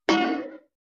Play, download and share vv_bonk cartoon original sound button!!!!
vv-bonk-cartoon.mp3